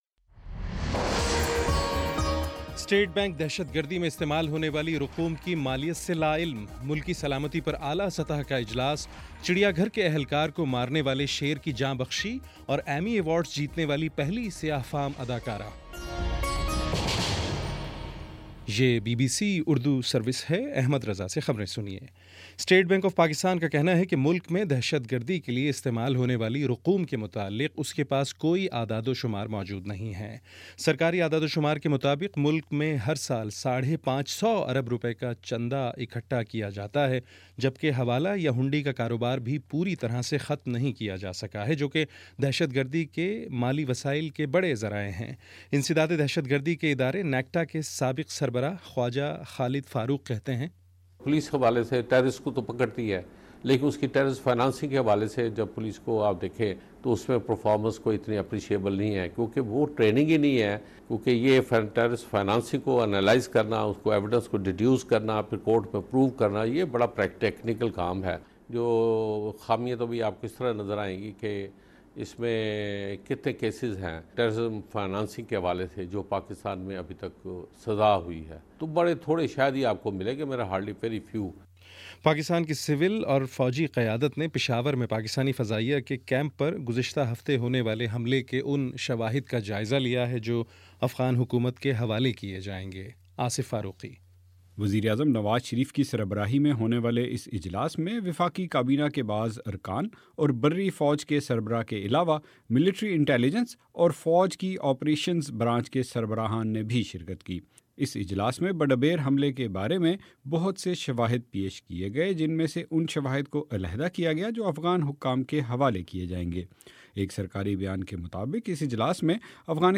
ستمبر21 : شام چھ بجے کا نیوز بُلیٹن